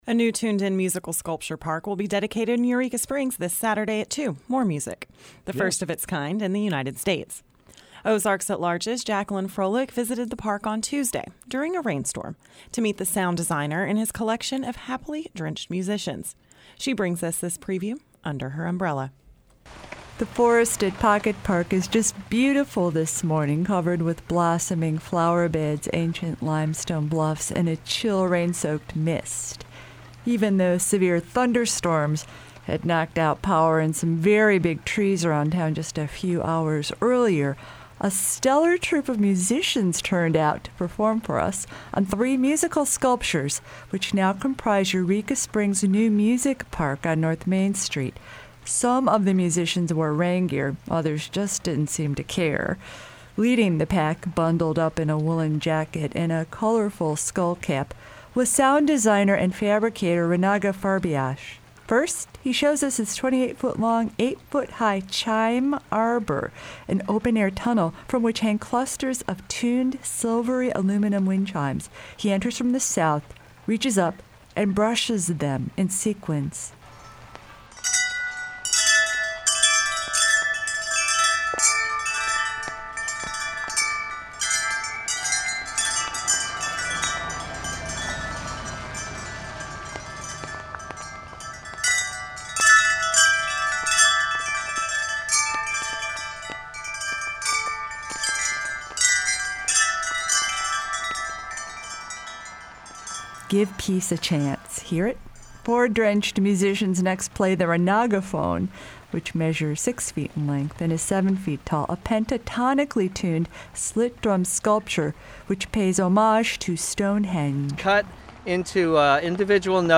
Three tuned chimes and marimba sculptures will be dedicated in Eureka Springs new Music Park on north Main Street, this Saturday at 2 p.m.
Music_Sculpture_Park.mp3